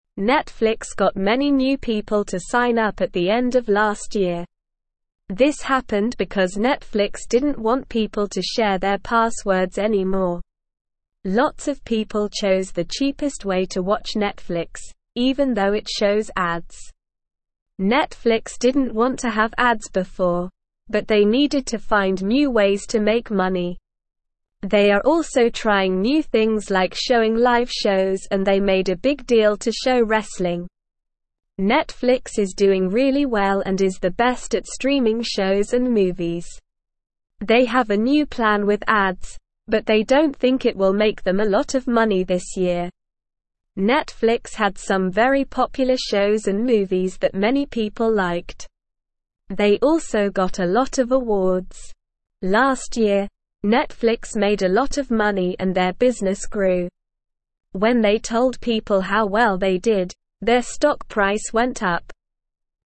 Slow
English-Newsroom-Beginner-SLOW-Reading-Netflix-Grows-with-New-Viewers-and-Ads.mp3